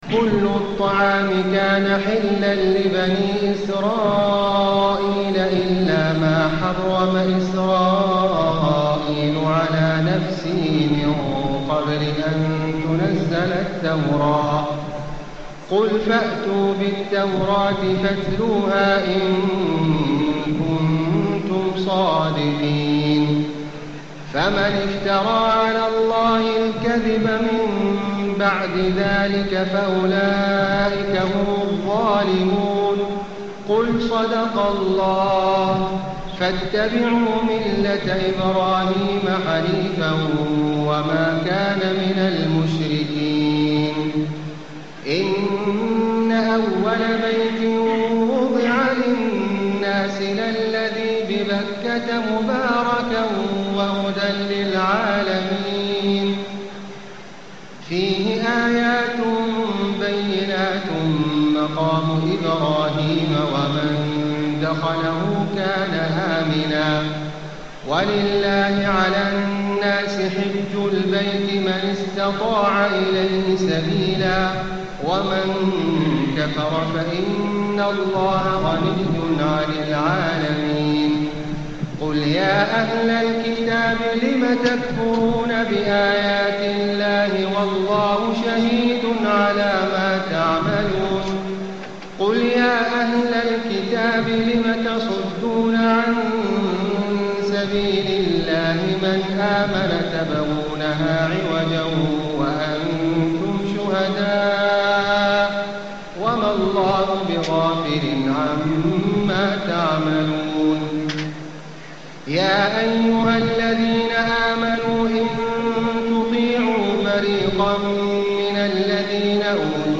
تهجد ليلة 24 رمضان 1435هـ من سورة آل عمران (93-185) Tahajjud 24 st night Ramadan 1435H from Surah Aal-i-Imraan > تراويح الحرم المكي عام 1435 🕋 > التراويح - تلاوات الحرمين